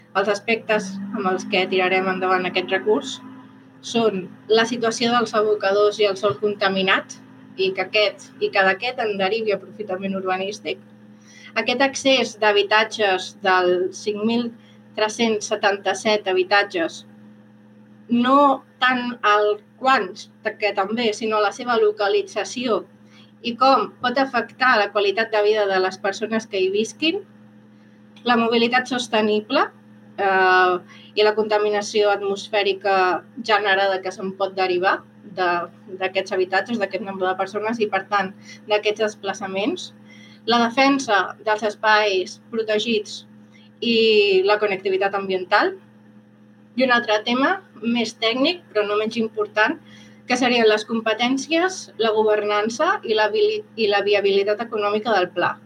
Declaracions
en roda de premsa